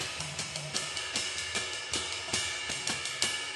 Closed Hats
RIDE_LOOP_13.wav